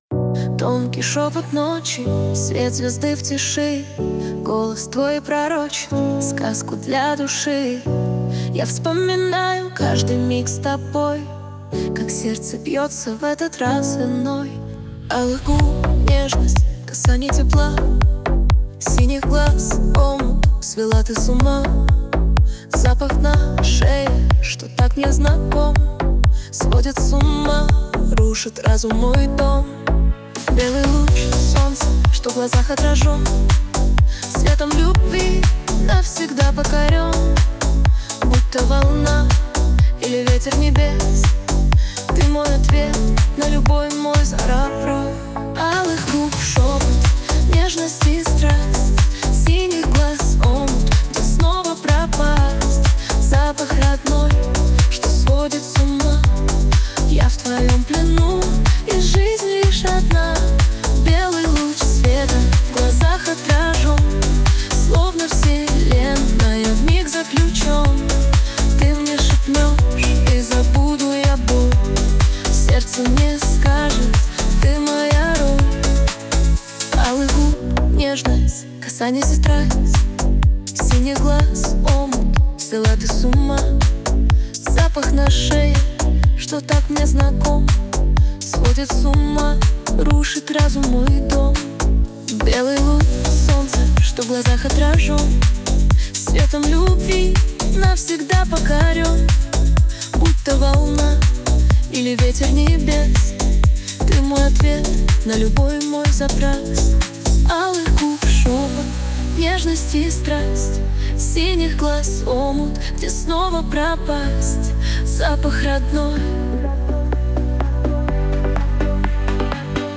RUS, Romantic, Lyric, Dance, Pop | 16.03.2025 10:51